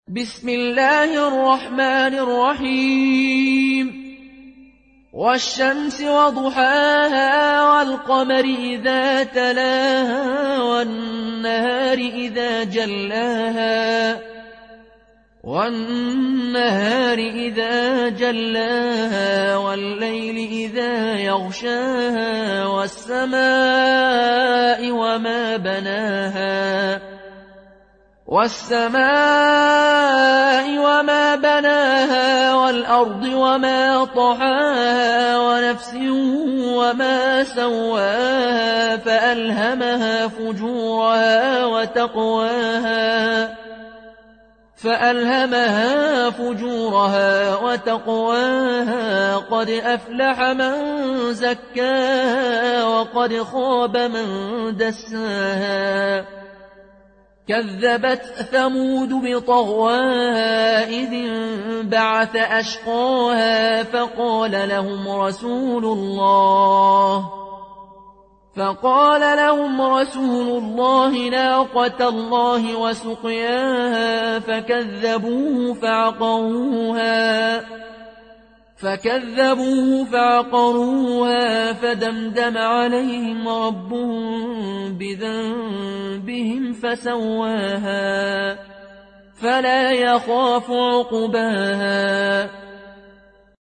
قالون عن نافع